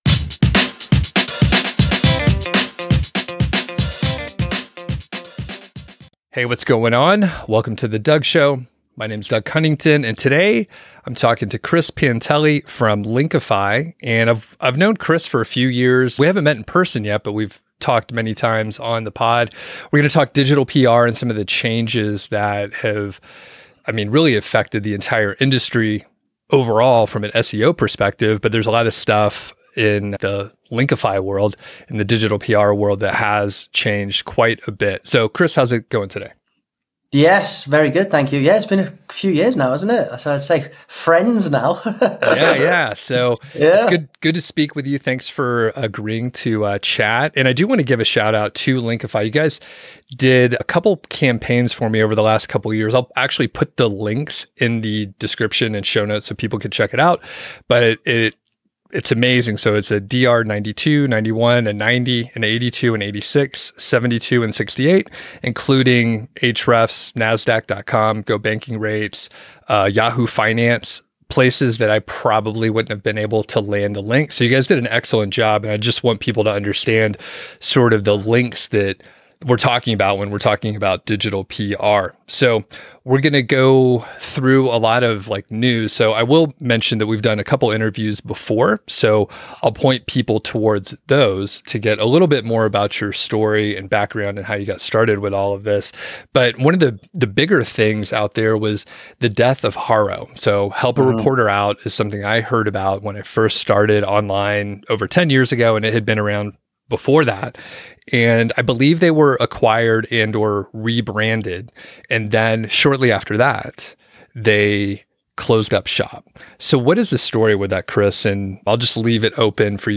in a lively discussion recorded at FinCon in 2023